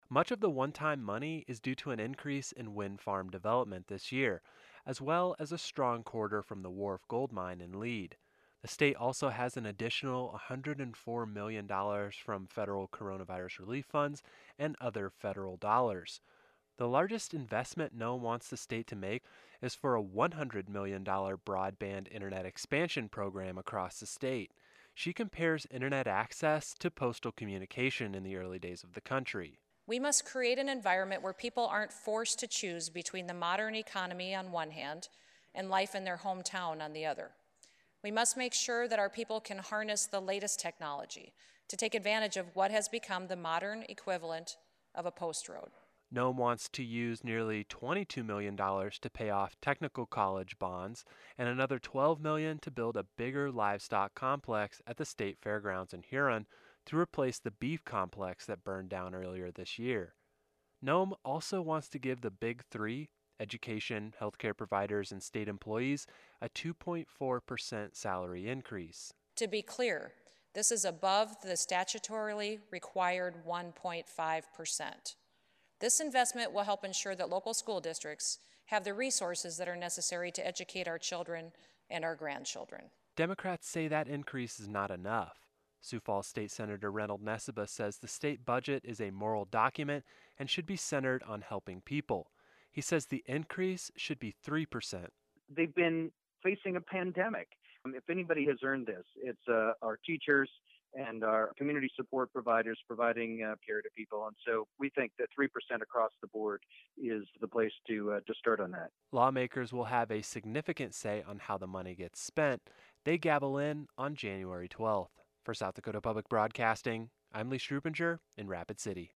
Noem delivers Dec 8th state budget address